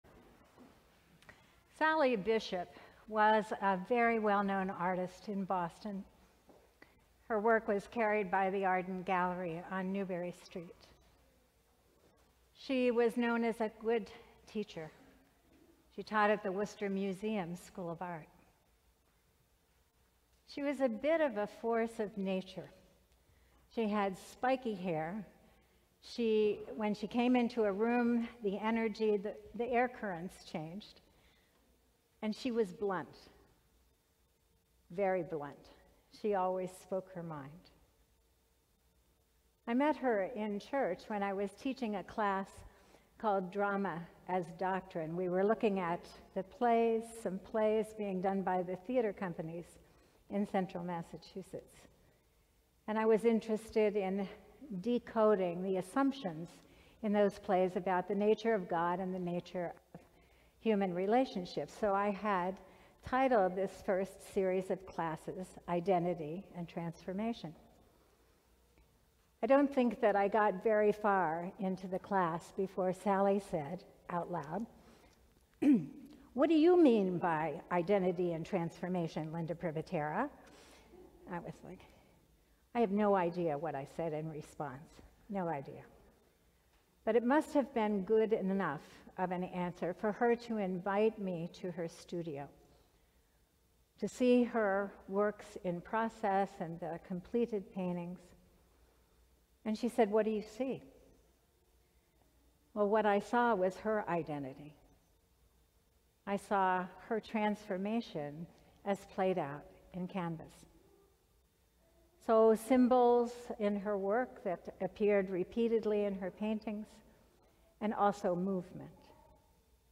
Sermon: Called Out - St. John's Cathedral